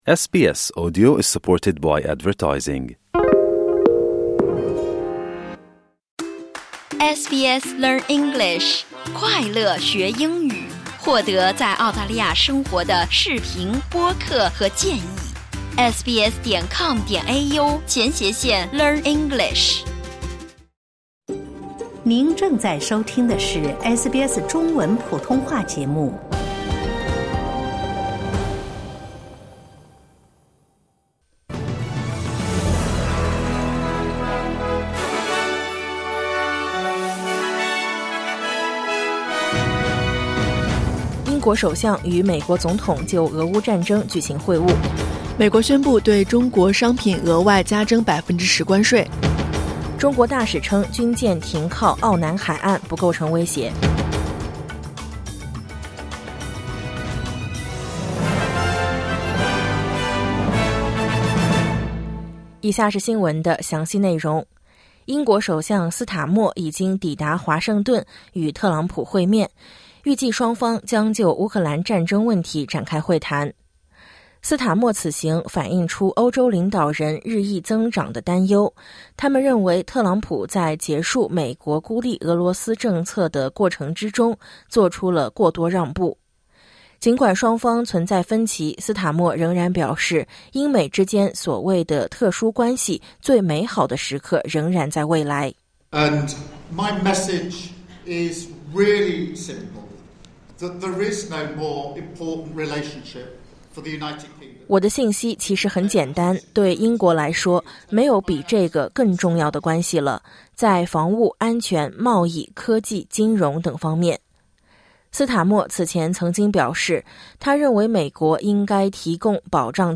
SBS早新闻（2025年2月28日）